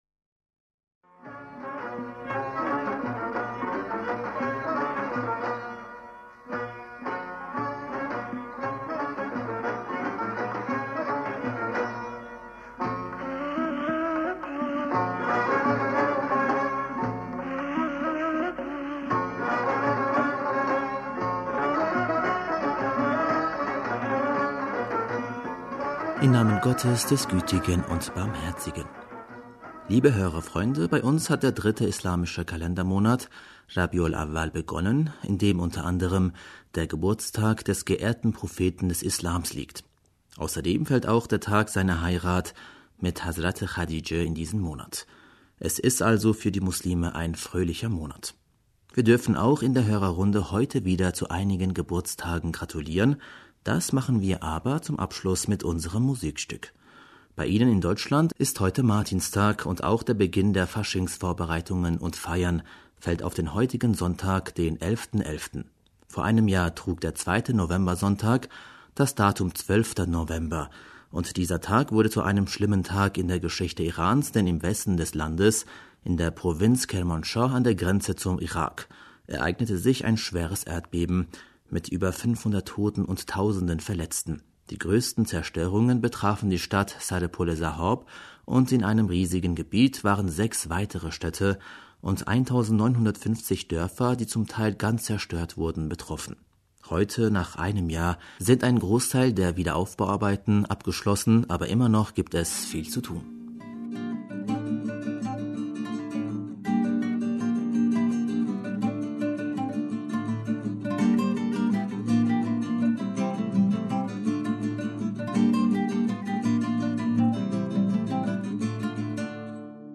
Hörerpostsendung am 11. November 2018